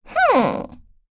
Pansirone-Vox_Think.wav